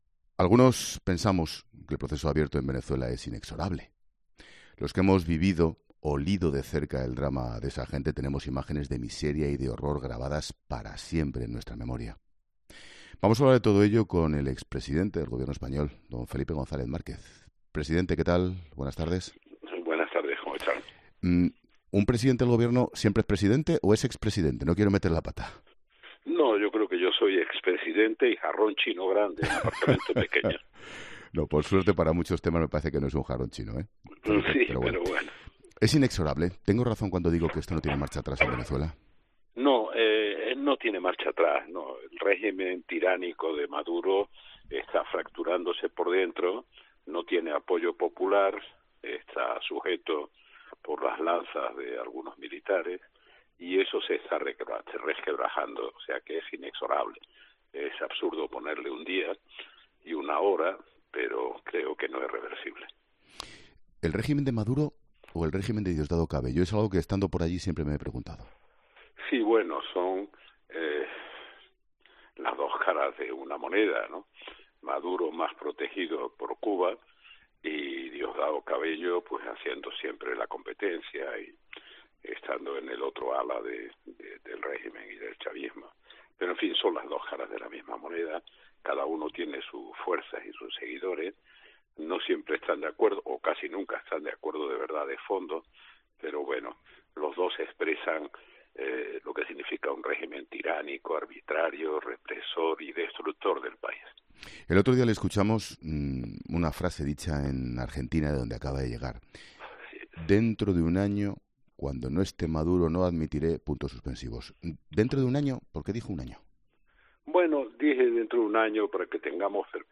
ESCUCHA LA ENTREVISTA COMPLETA EN 'LA LINTERNA' En este punto y preguntado sobre si la defensa de Maduro responde a un complejo de la izquierda.